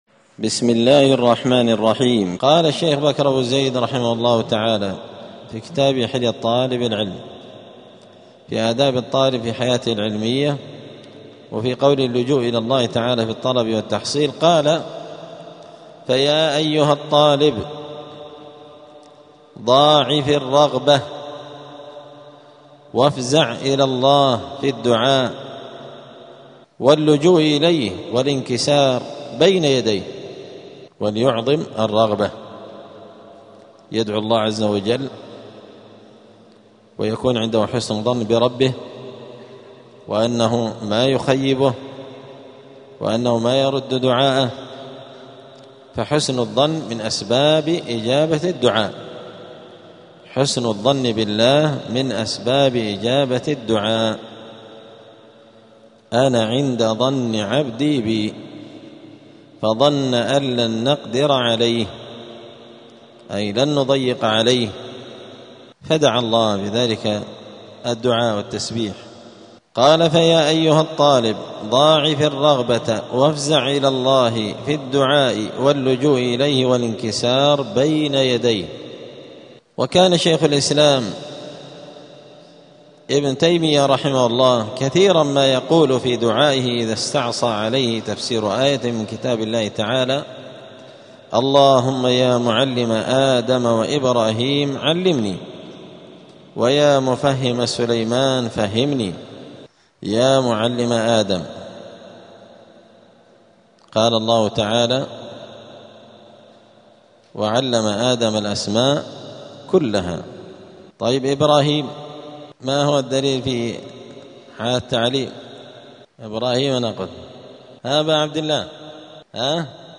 الأربعاء 18 رجب 1447 هــــ | الدروس، حلية طالب العلم، دروس الآداب | شارك بتعليقك | 8 المشاهدات